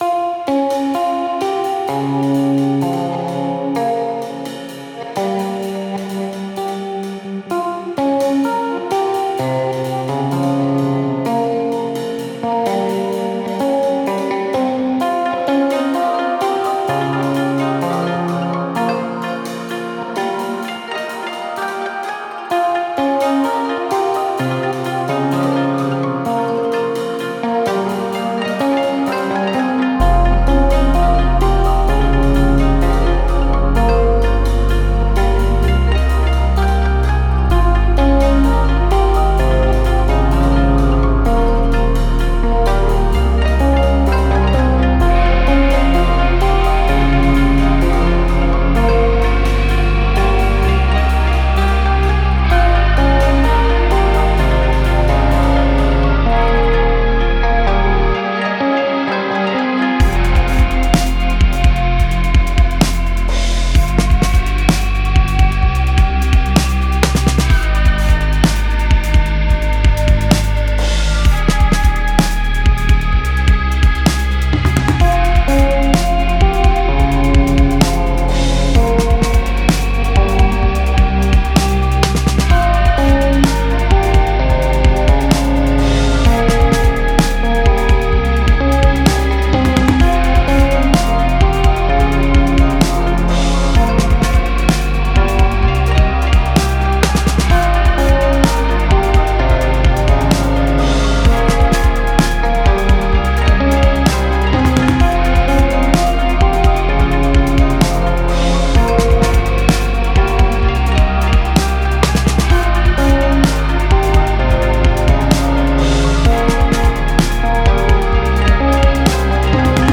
glad you like it. there is also a /calm/ version, but I don't like it as much personally